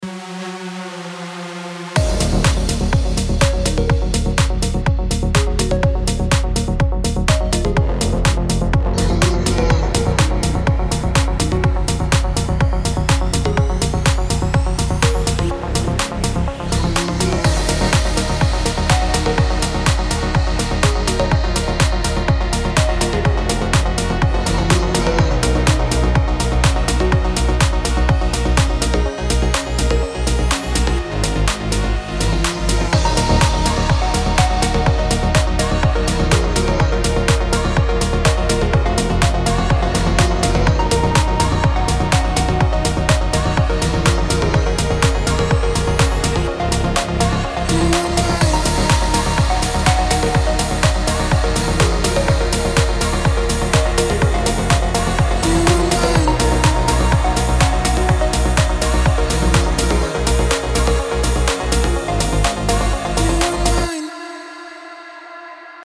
Оцените, пожалуйста, музыкальный баланс, планы, объем и т.д. Всё сделано в Logic. Мониторинг: посредственное ближнее поле, на среднем и дальнем поле не проверял, посредственные наушники и ЦАП.